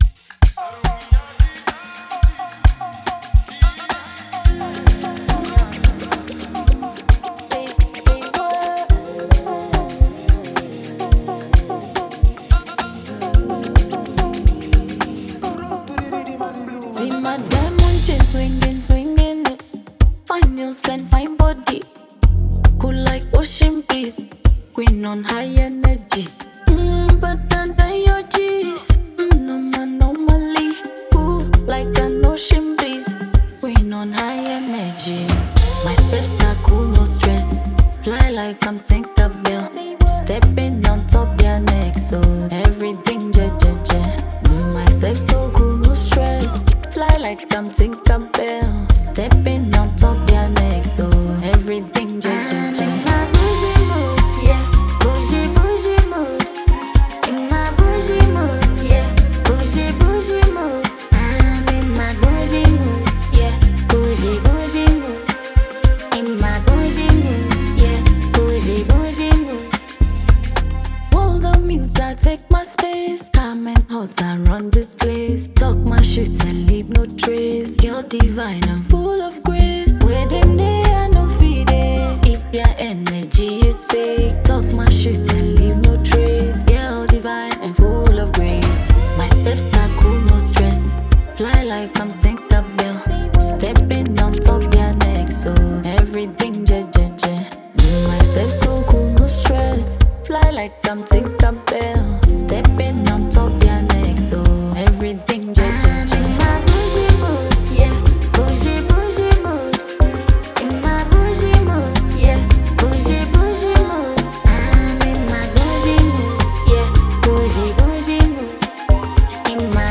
confidence-filled and stylish anthem
Built on a vibrant Afropop rhythm
catchy percussion, bright synths, and an upbeat groove